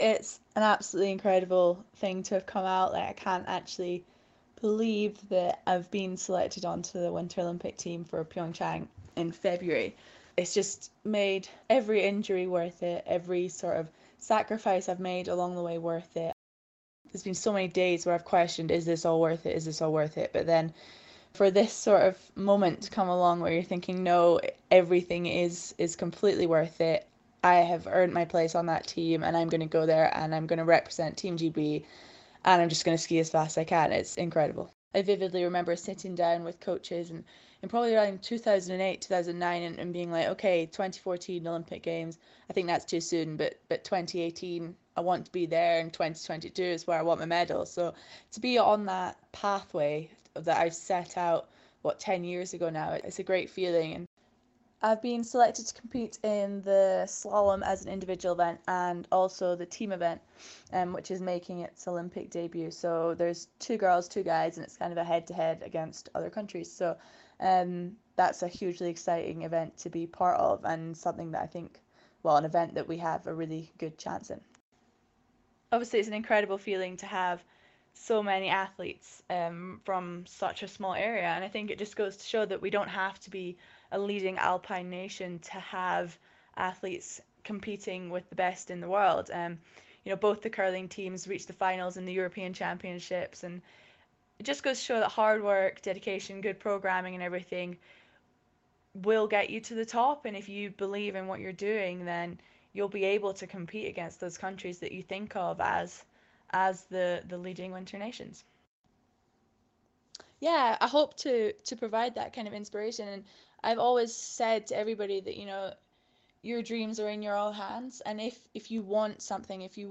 Perth slalom skier, Charlie Guest, is talking to us after being selected for her first Winter Olympics in Pyeongchang next month.